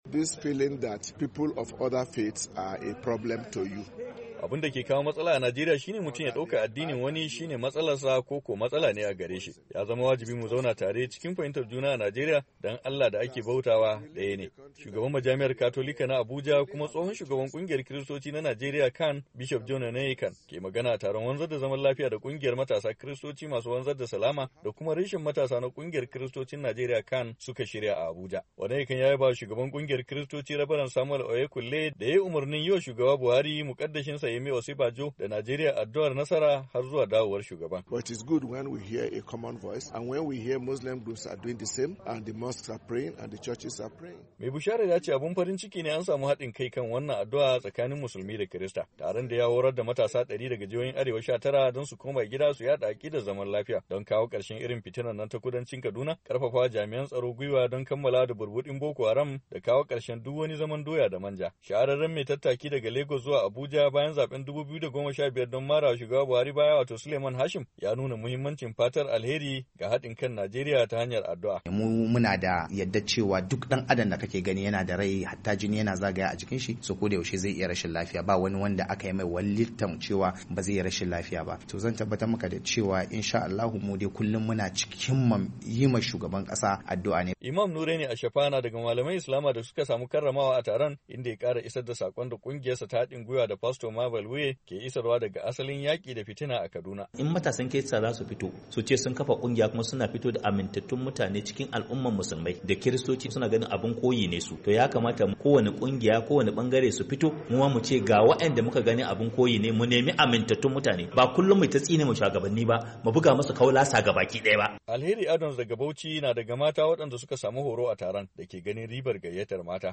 Taron Kungiyoyin Matasa Kristoci a Abuja